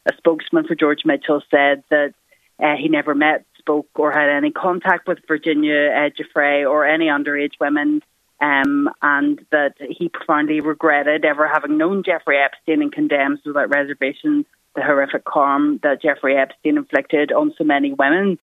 Freelance journalist